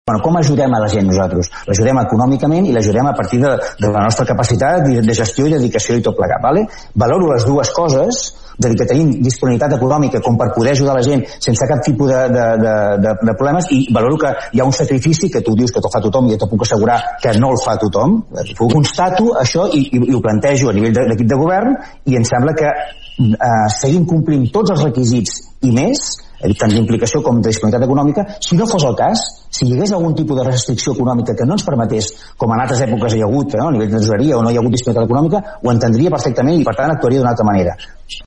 El ple d’ahir, de poc més de 2 hores, es va fer de forma telemàtica amb tots els regidors connectats a través d’internet des de casa seva cosa que va provocar alguns problemes tècnics i de qualitat del so.
alcalde-sous.mp3